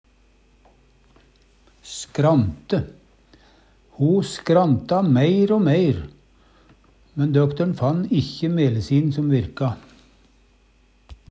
skrante - Numedalsmål (en-US)